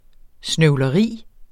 Udtale [ snœwlʌˈʁiˀ ]